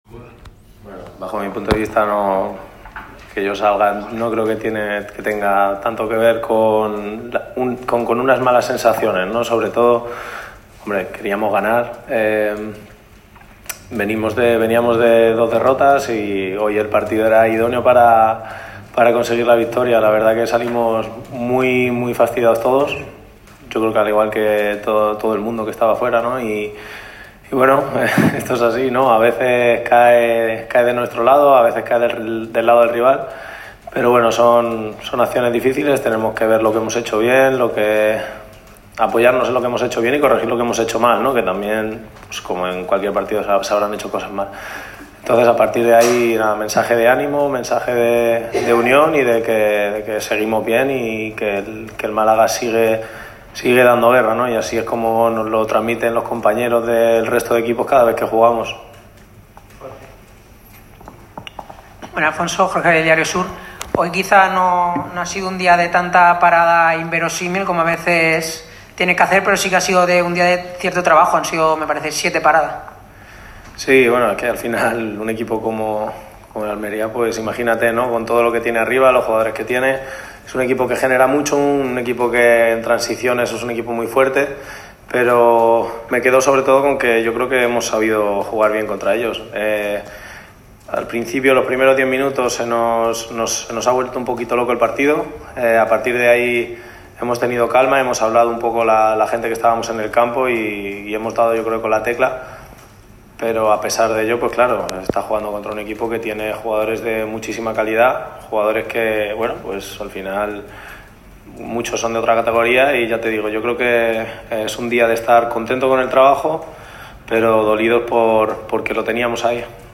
El meta toledano ha comparecido ante los medios de comunicación al término del duelo que ha medido a los boquerones contra el UD Almería. El resultado final fue de empate a uno tras el gol de Dioni en la primera mitad y el tanto de Álex Pastor en propia puerta en el 96′.